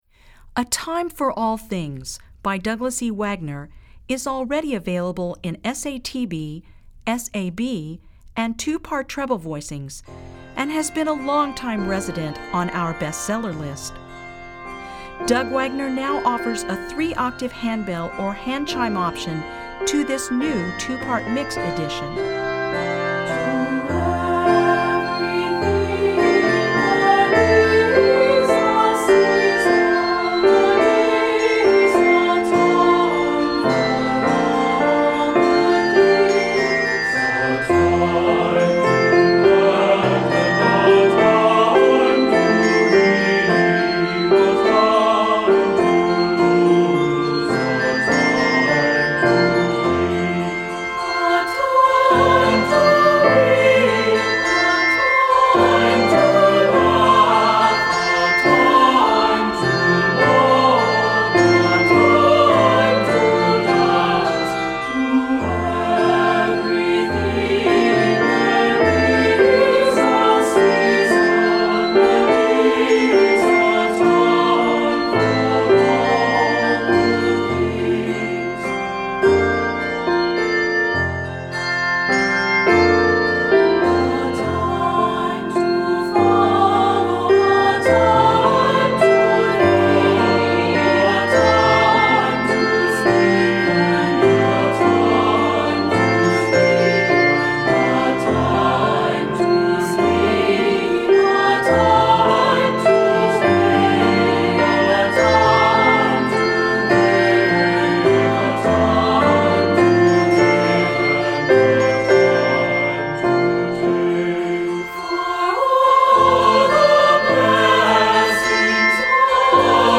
Voicing: Unison|2-Part Level